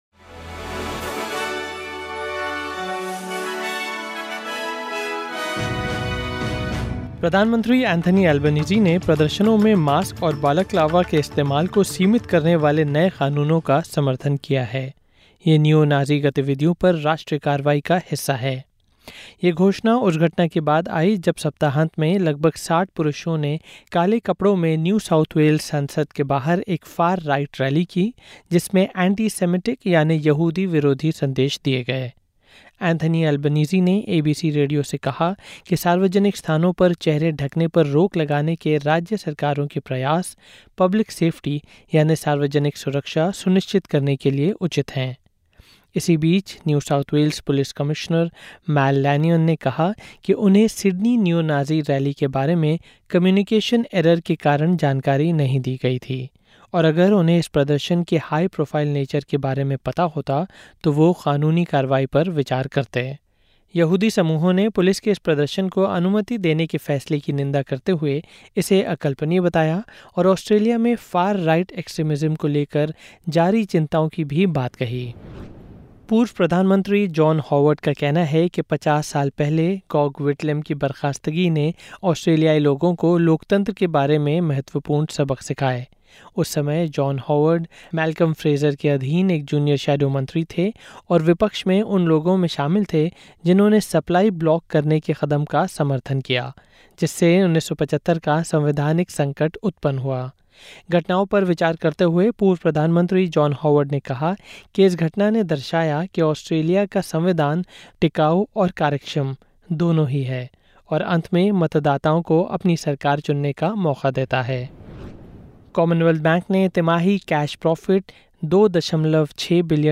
ऑस्ट्रेलिया और भारत से 11/11/2025 के प्रमुख समाचार हिंदी में सुनें।